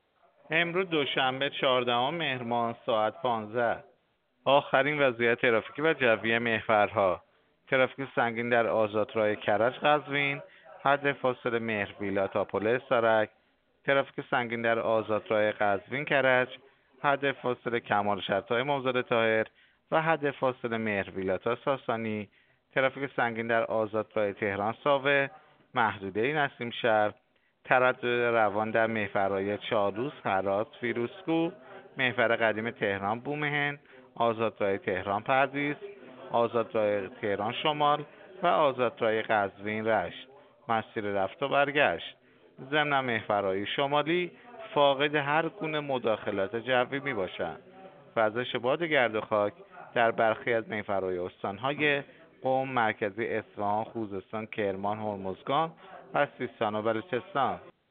گزارش رادیو اینترنتی از آخرین وضعیت ترافیکی جاده‌ها ساعت ۱۵ چهاردهم مهر؛